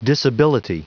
Prononciation du mot disability en anglais (fichier audio)
Prononciation du mot : disability